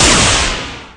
Thunder10.ogg